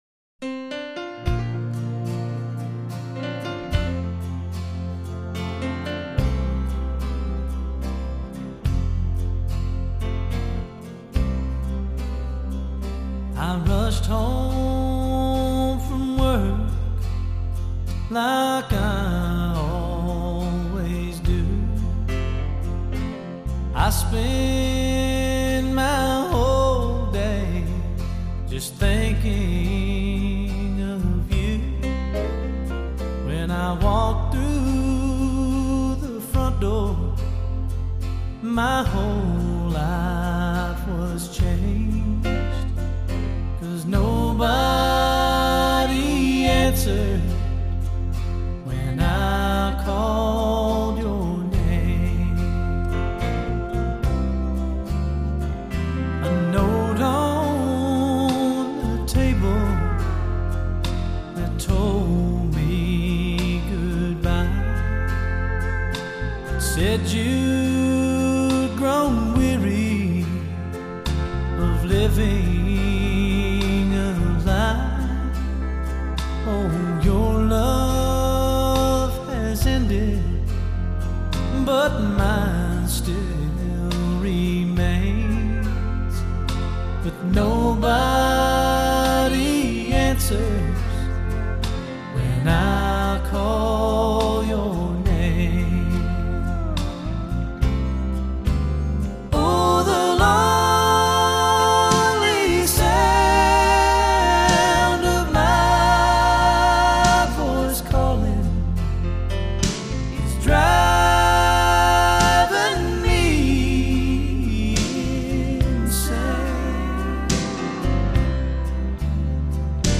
音乐类型：乡村